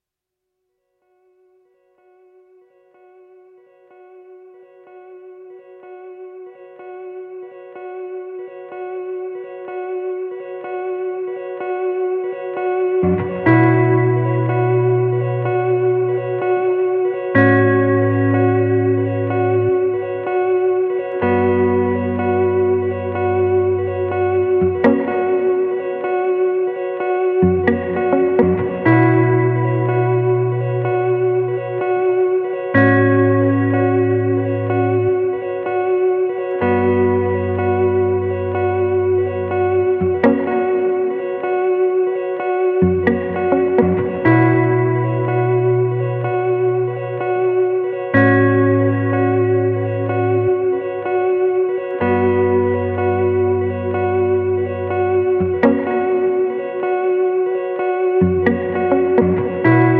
sunshine boogie, cosmic disco and lashings of Balearic funk